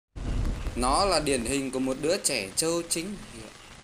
Thể loại: Câu nói Viral Việt Nam
Giọng nói pha chút hài hước tạo nên tiếng cười cho người xem. Âm thanh phát ra nó mang ý nghĩa chê bai một ai đó có tính cách trẻ trâu.